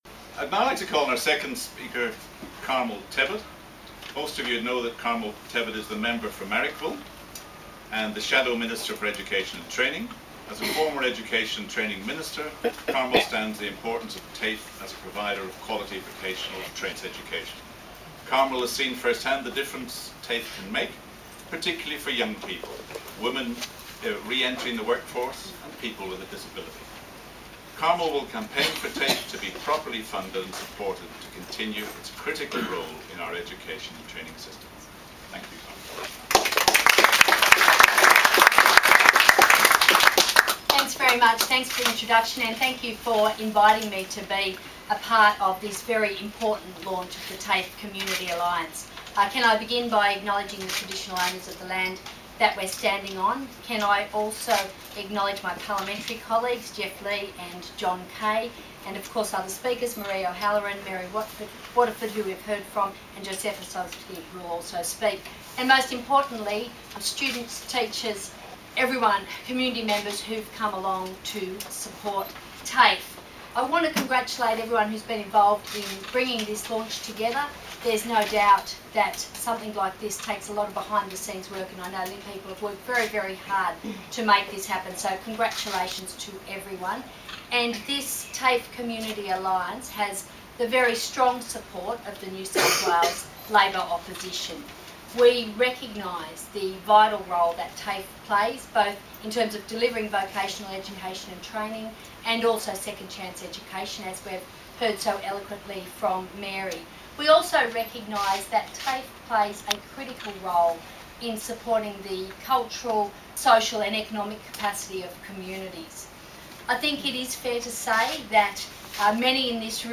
On February 22 2013 the TAFE Community Alliance was formally launched in the heart of western Sydney at Western Sydney Community Forum, Parramatta.